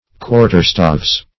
Search Result for " quarterstaves" : The Collaborative International Dictionary of English v.0.48: Quarterstaff \Quar"ter*staff`\, n.; pl. Quarterstaves .
quarterstaves.mp3